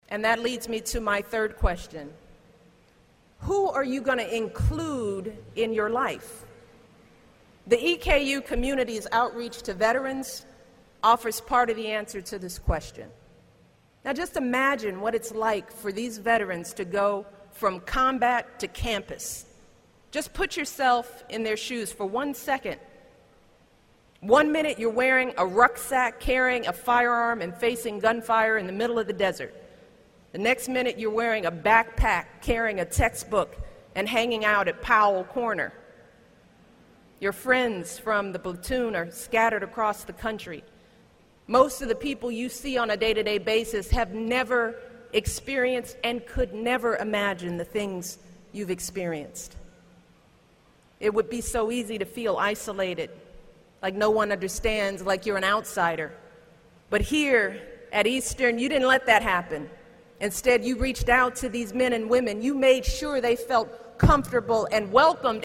公众人物毕业演讲第272期:米歇尔2013东肯塔基大学13 听力文件下载—在线英语听力室